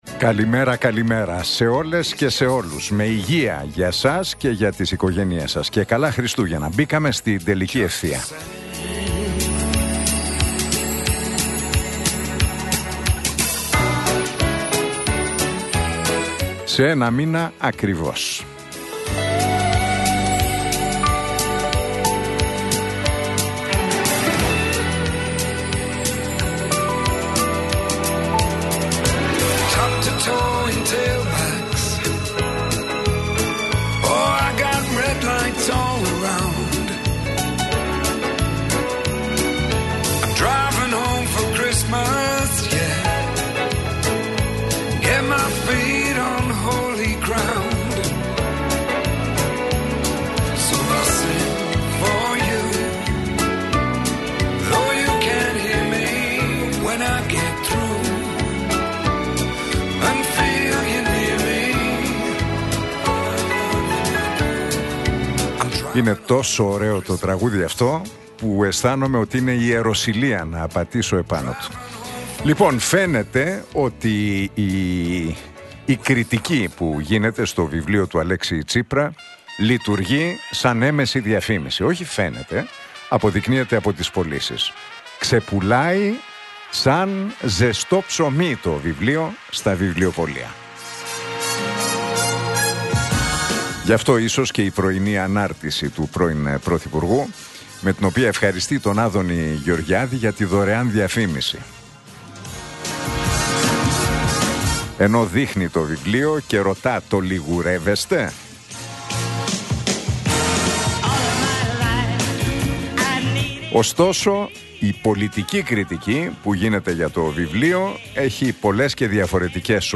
Ακούστε το σχόλιο του Νίκου Χατζηνικολάου στον ραδιοφωνικό σταθμό Realfm 97,8, την Τρίτη 25 Νοεμβρίου 2025.